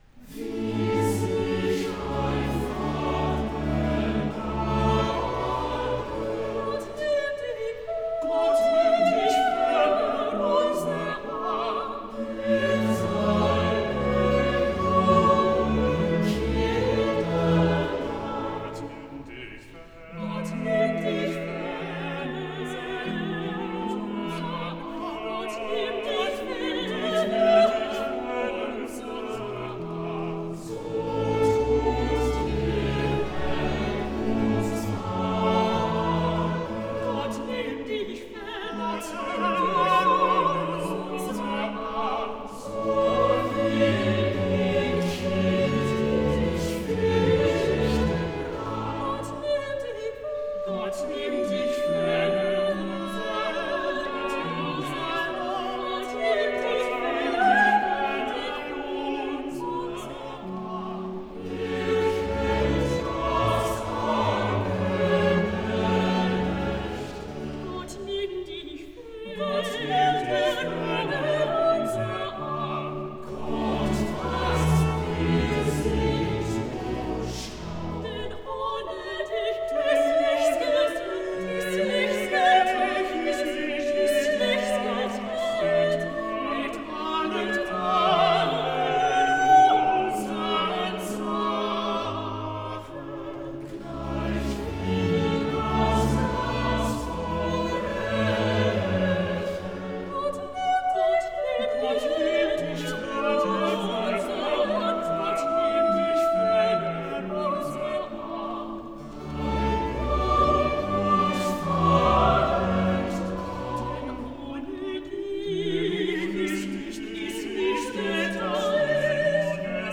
soprano
Choir & Chamber Works: